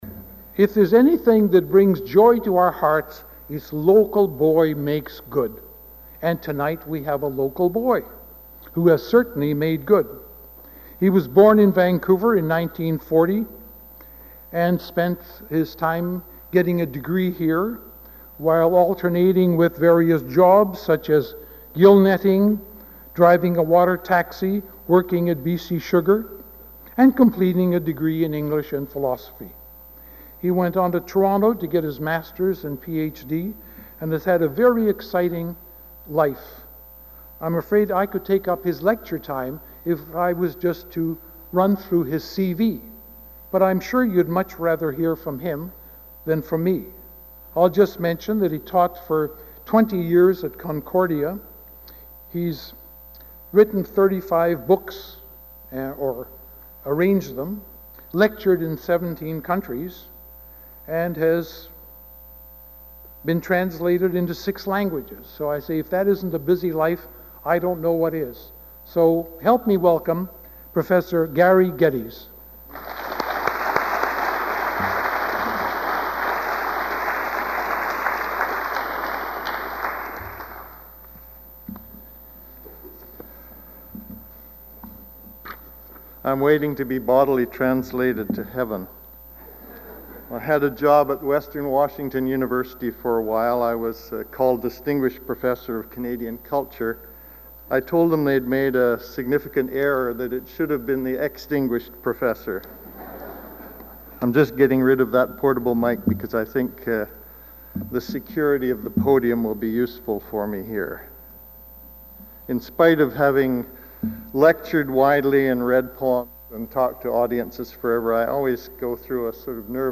Item consists of a digitized copy of an audio recording of a Cecil and Ida Green Lecture delivered at the Vancouver Institute by Gary Geddes on November 12, 2005.